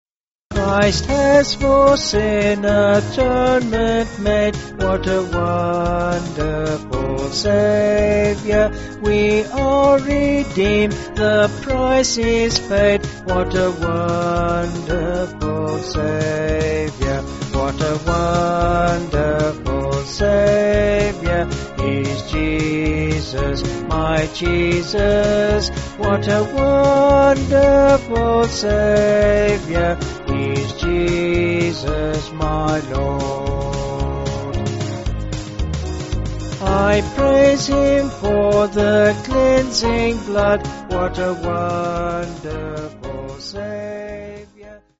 (BH)   4/Db-D
Vocals and Band